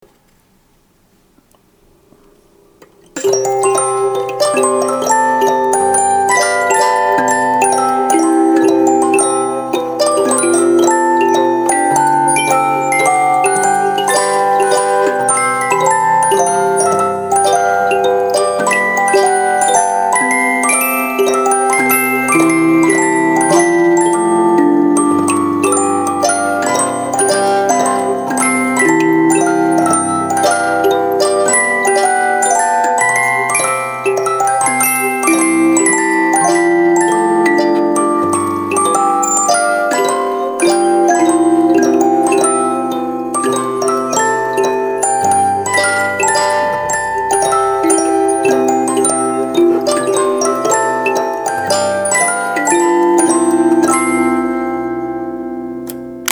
Unknown Tunes on Ducommun Giraud Cylinder, Part 2